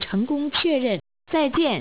confirm.wav